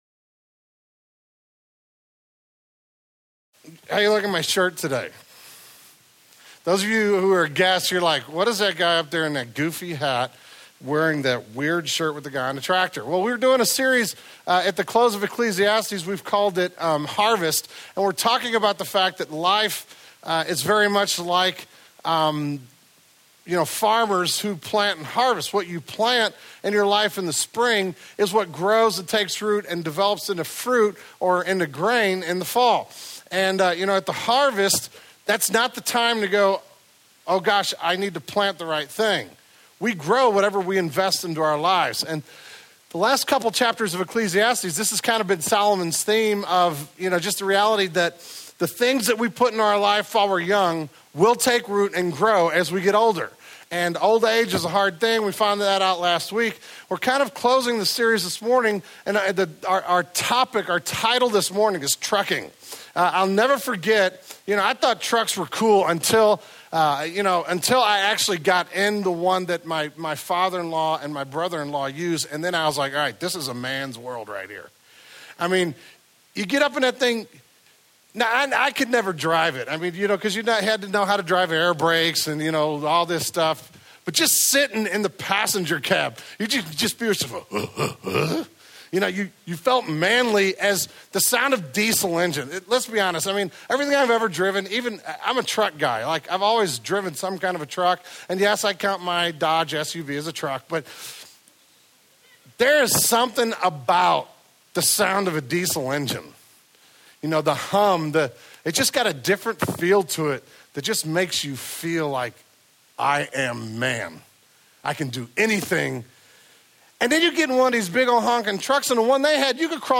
This is the last message in the series on the Old Testament book of Ecclesiastes. For several months, we have been exploring King Solomon's words of wisdom challenging us to spend our lives wisely, and live for the only One worthy of our lives. Solomon sticks the landing, as he explains why he took time to write this book.